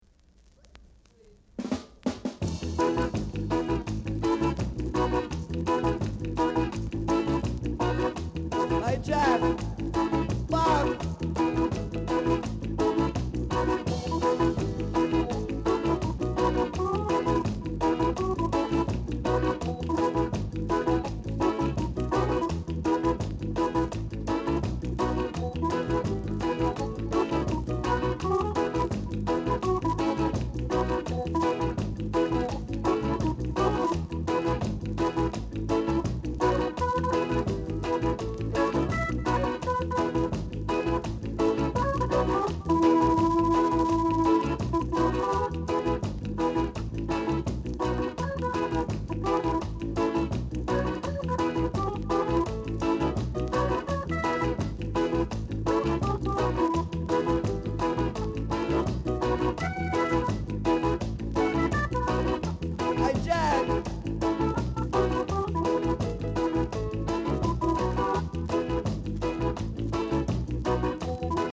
Reggae Inst